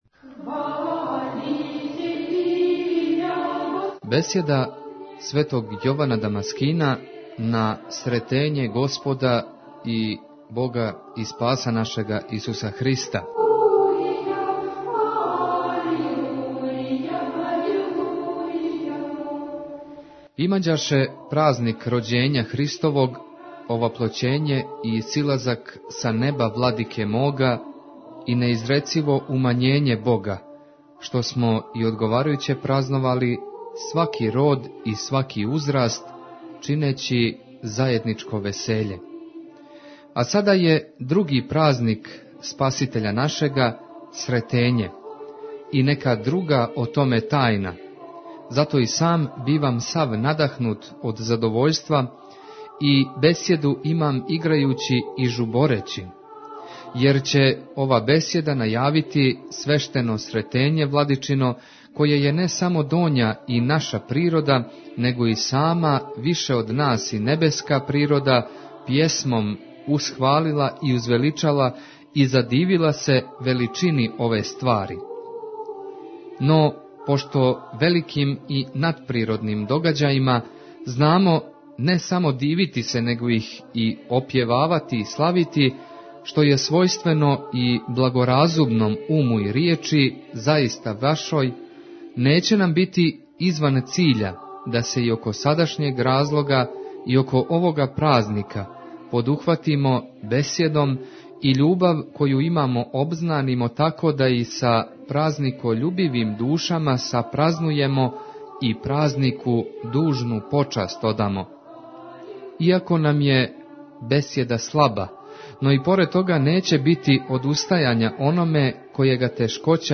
Бесједа Светог Јована Дамаскина на Сретење Господње Tagged: Из наше Цркве 53:52 минута (9.25 МБ) Бесједа Светог Јована Дамаскина на празник Сретење Господње.